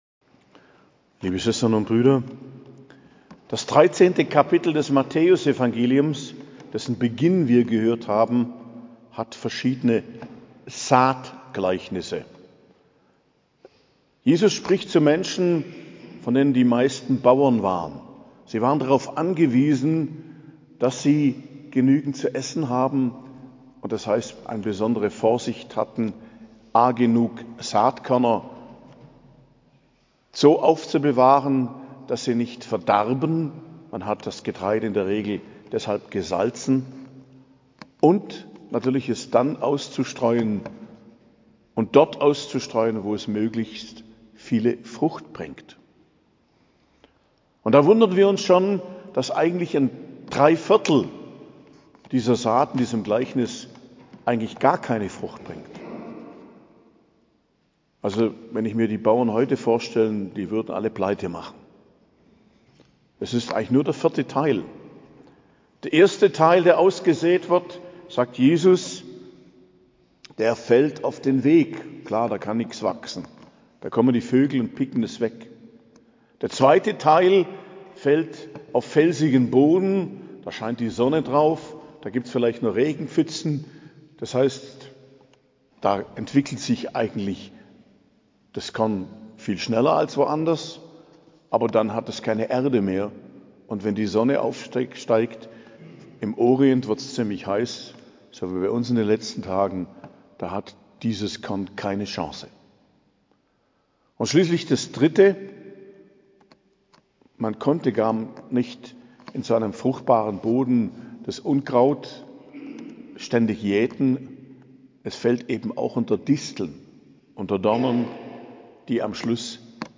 Predigt zum 15. Sonntag i.J., 16.07.2023